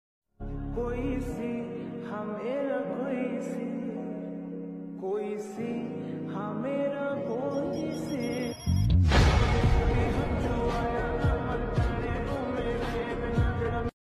Honda 125 Bike Sound Effects Free Download